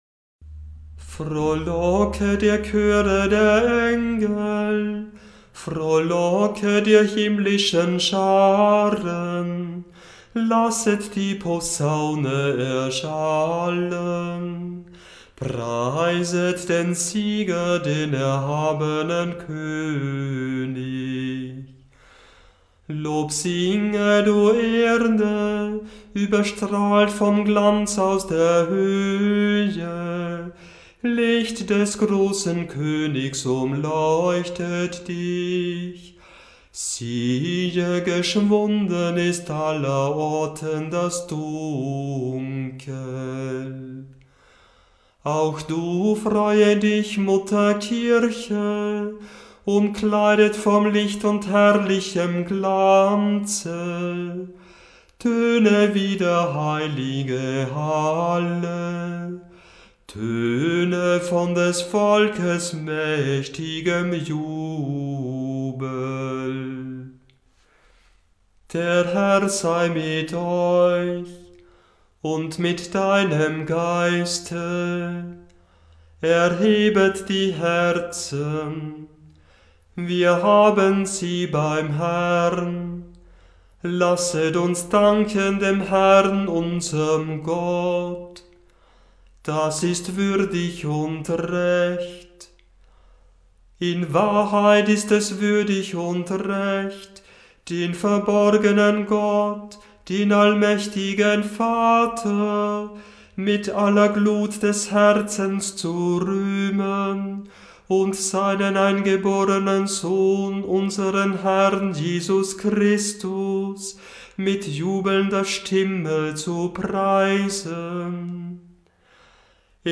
Liturgische Gesänge
osternacht-exsultet.mp3